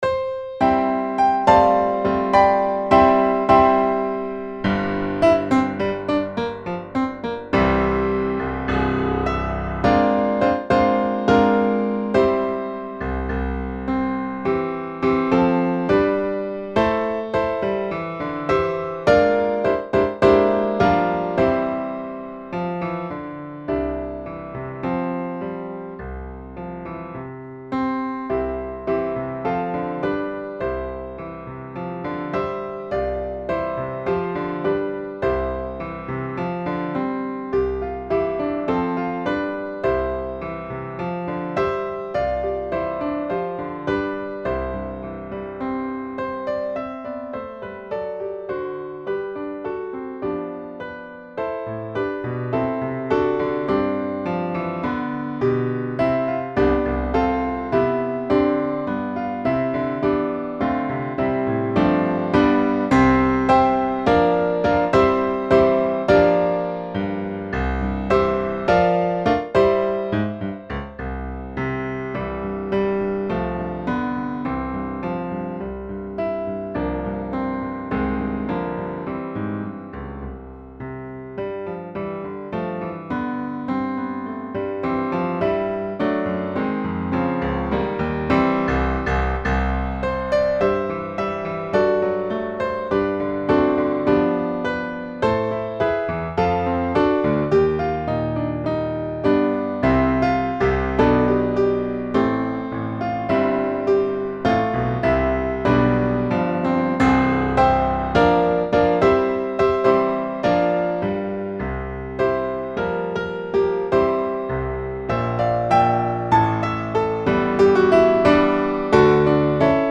Voicing: Piano